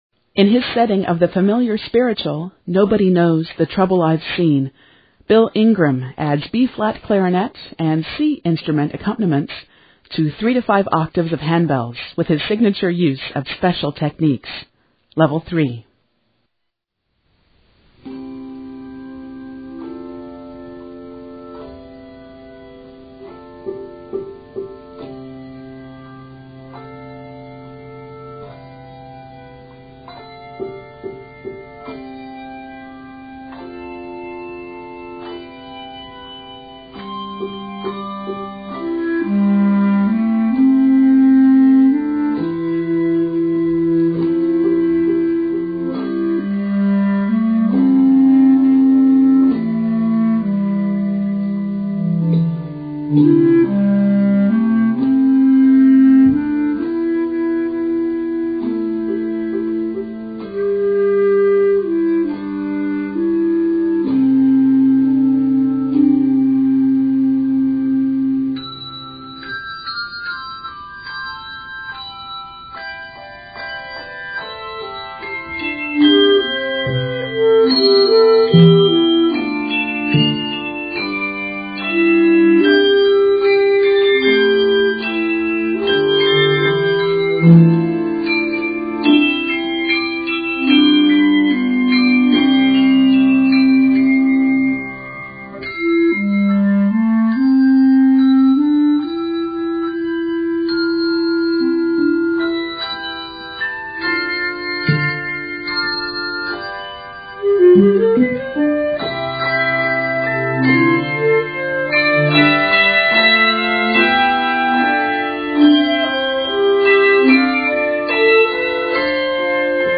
with his signature use of special techniques